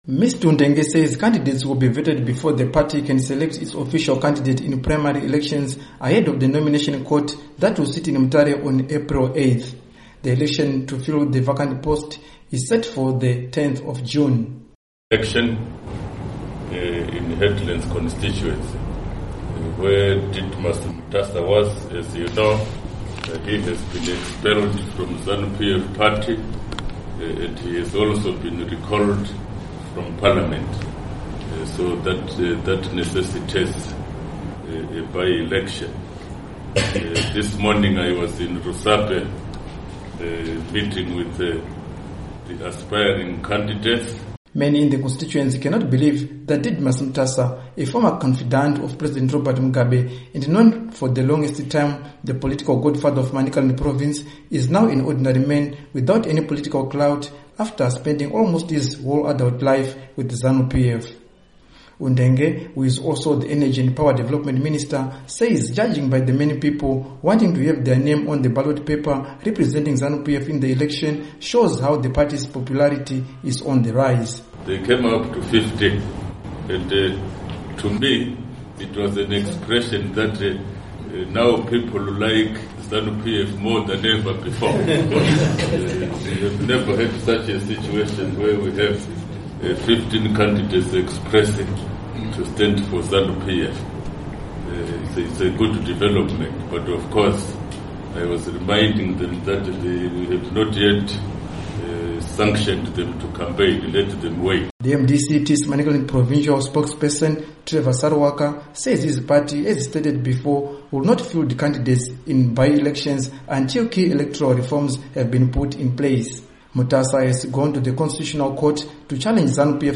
Zanu PF Primary Election Report